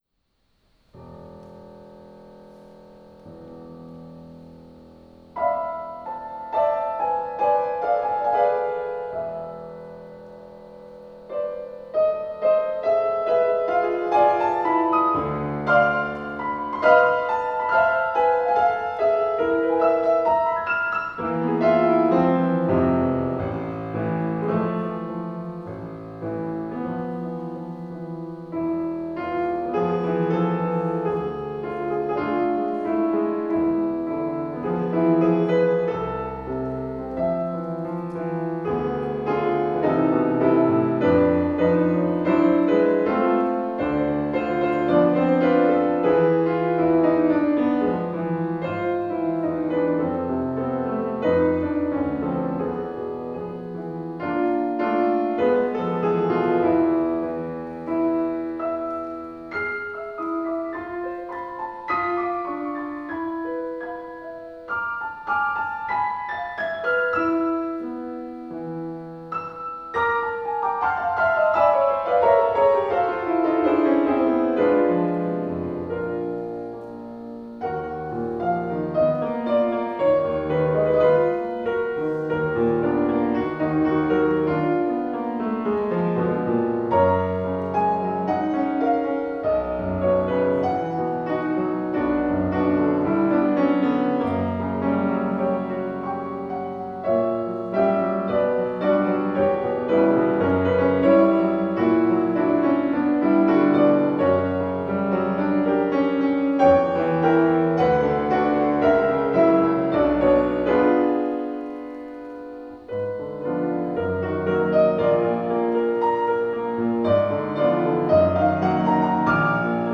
2022-09-19 Jews in the Musical Culture of Galicia/ fortepian
Koncert odbył się w sali koncertowej Swarthout Recital Hall. Zgromadzona licznie publiczność miała możliwość poznać współczesną, polską muzykę wokalno-instrumentalną skomponowaną przez Krzysztofa Kostrzewę i Ewę Nidecką oraz 3 parafrazy na temat żydowskich melodii ludowych Juliusza Wolfsohna na fortepian solo.